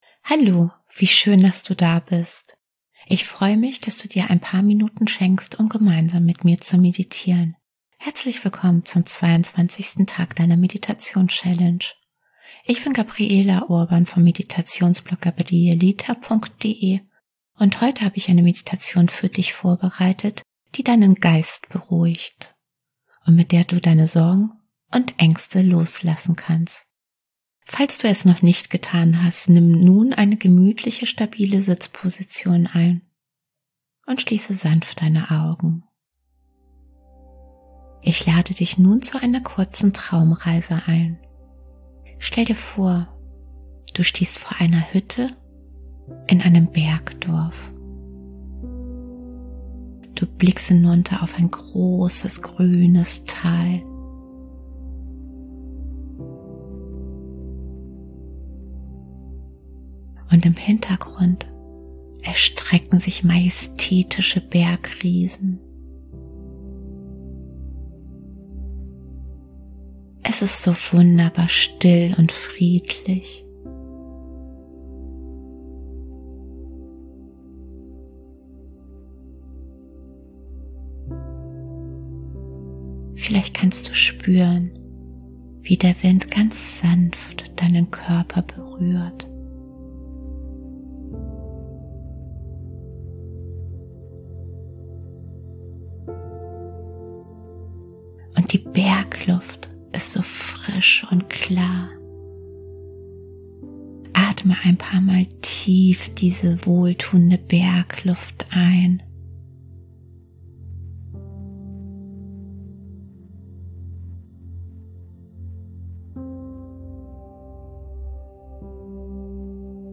Traumreisen & geführte Meditationen